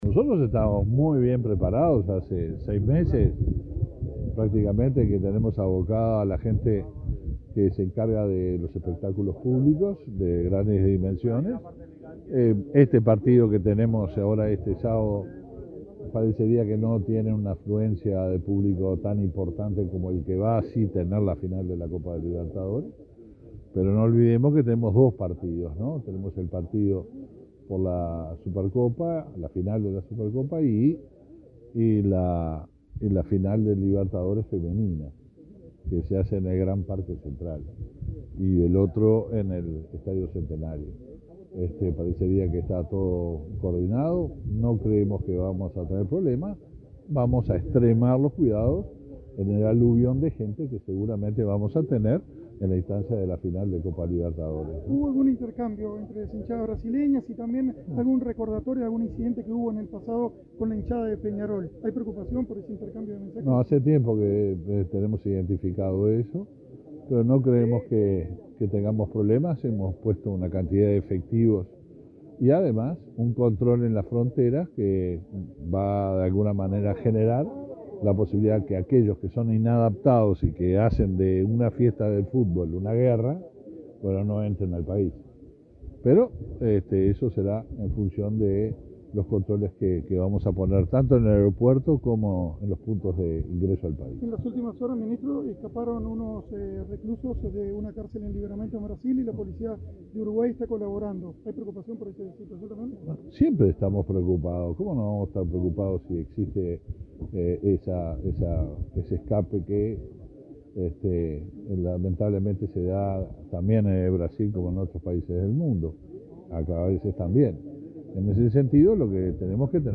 Declaraciones a la prensa del ministro del Interior, Luis Alberto Heber
Declaraciones a la prensa del ministro del Interior, Luis Alberto Heber 18/11/2021 Compartir Facebook X Copiar enlace WhatsApp LinkedIn Este 18 de noviembre, el Ministerio del Interior inauguró un establecimiento anexo a la cárcel de mujeres, en Artigas. Tras el acto, el ministro Heber efectuó declaraciones a la prensa.